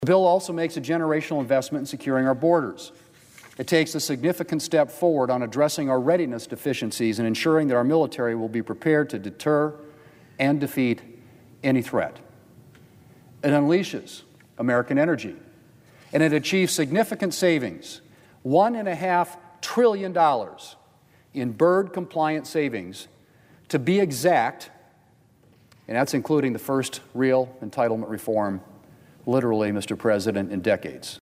WASHINGTON, D.C.(HubCityRadio)- On Wednesday, Senate Majority Leader John Thune was on the floor of the U.S. Senate to reflect on the first six months of the session.